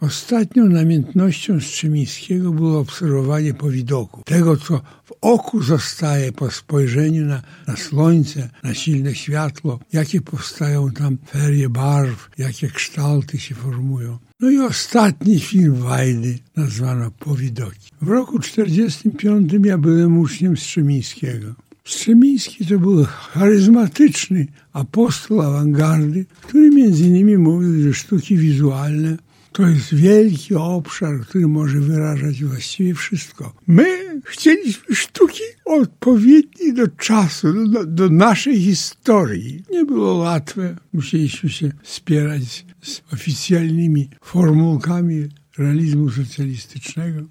We wtorek (18.02) na antenie Radia 5 autor opowiadał o swoim dziele.